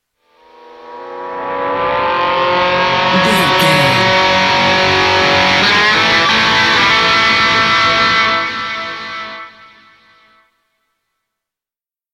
Epic / Action
Ionian/Major
electric guitar
Southern Rock
blues rock
hard rock
driving
lead guitar
Slide Guitar
aggressive
energetic
intense
powerful